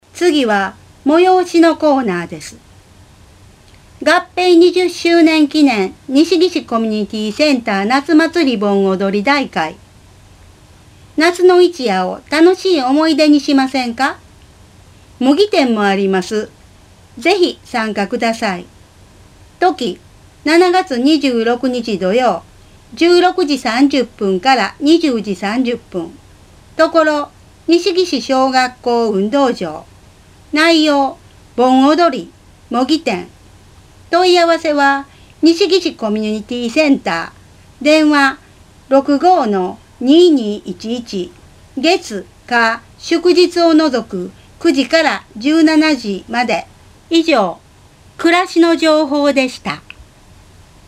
「広報紀の川」の音声版を、MP3形式の音声ファイルでダウンロードしていただけます。